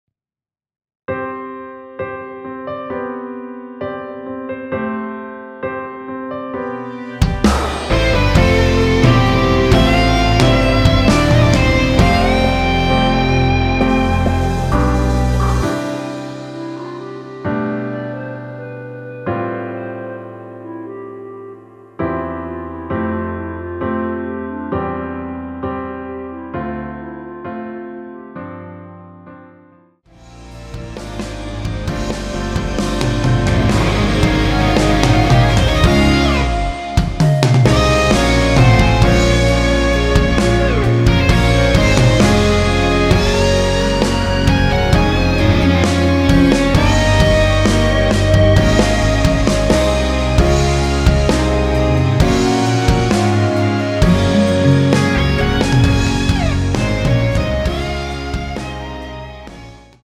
원키에서(-1)내린 멜로디 포함된 MR입니다.(미리듣기 확인)
앞부분30초, 뒷부분30초씩 편집해서 올려 드리고 있습니다.
중간에 음이 끈어지고 다시 나오는 이유는